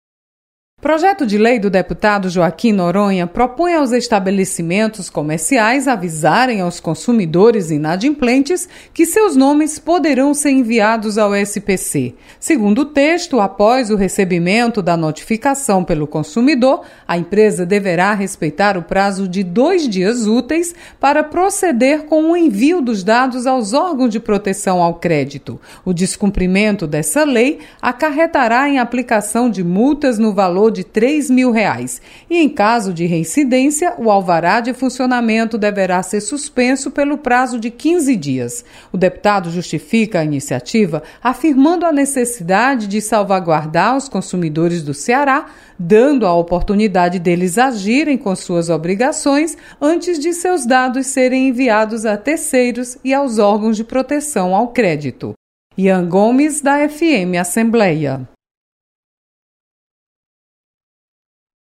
Projeto amplia direitos dos consumidores. Repórter